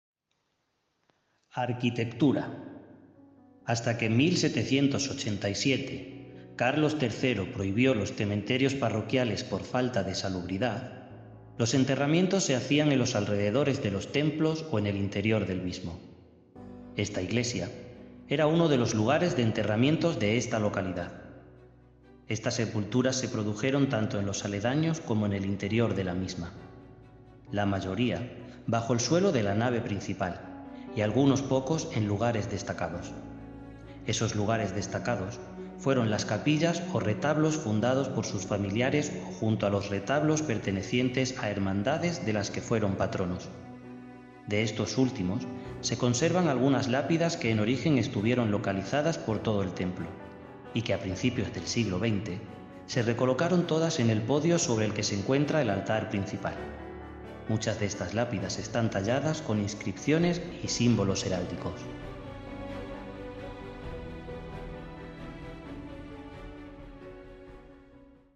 Cabecera de la Iglesia. Retablo principal / Chancel-Main Altarpiece » Arquitectura / Architecture Para mejor uso y disfrute colóquese los auriculares y prueba esta experiencia de sonido envolvente con tecnología 8D.